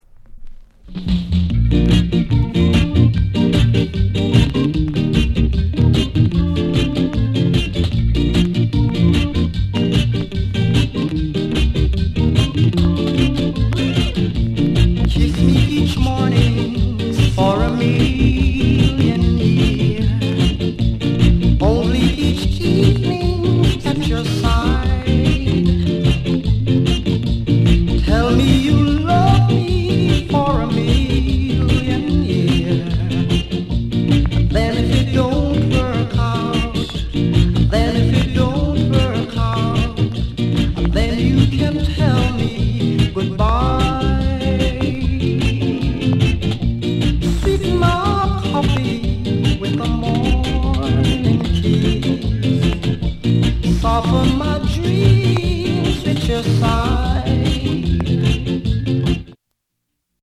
SKINHEAD